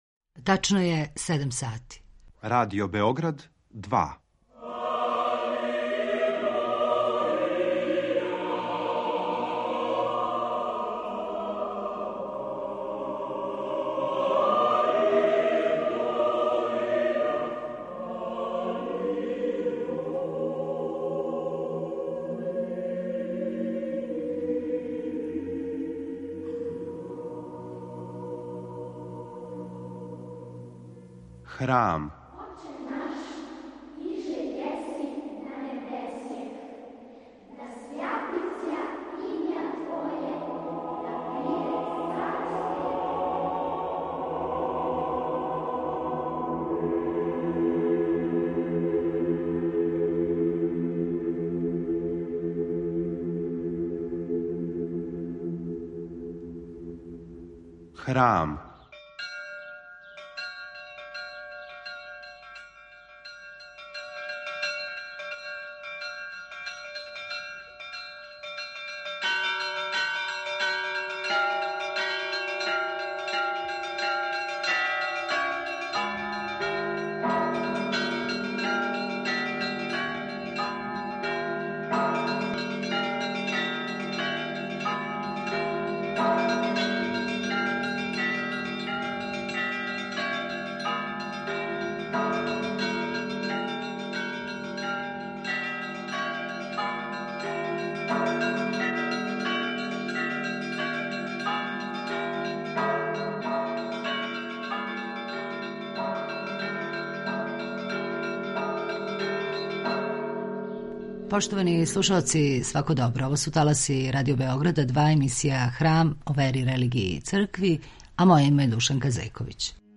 Гошћа је историчарка уметности